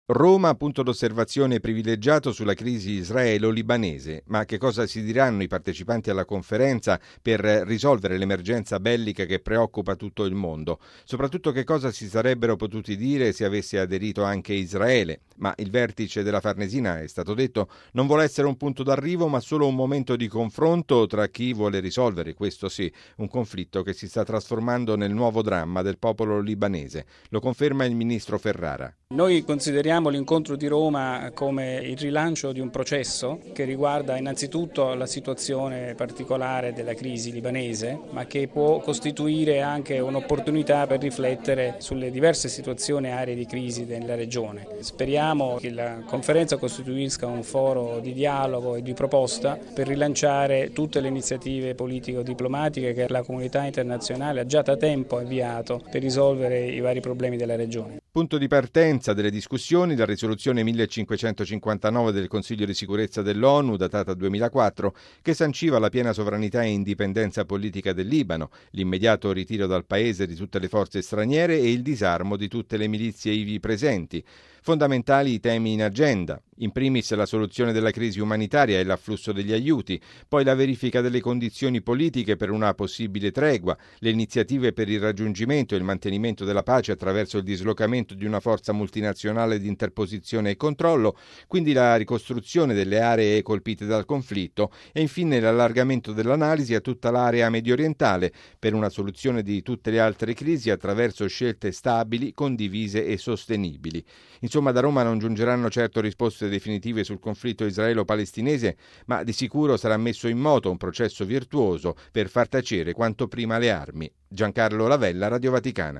Il servizio del nostro inviato alla Farnesina